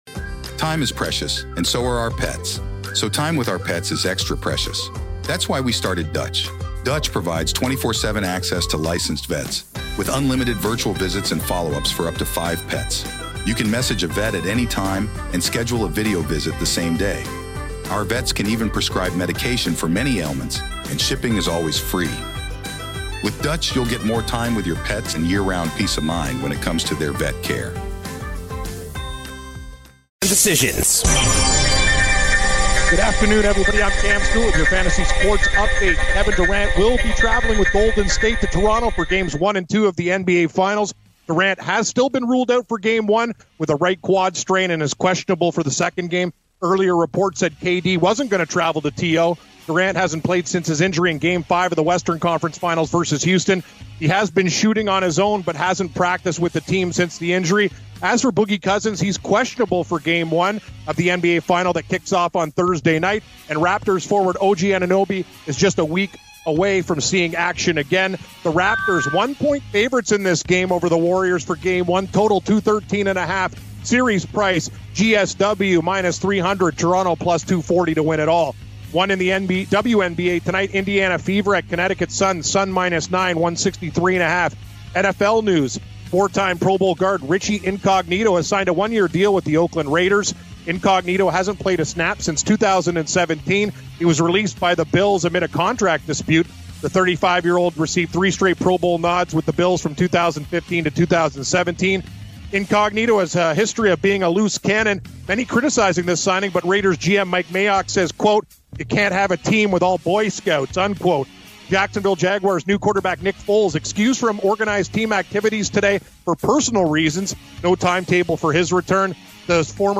They preview the MLB slate and preview their DFS lineup as well. Lastly, the guys re-air their interview with Leo Rautins.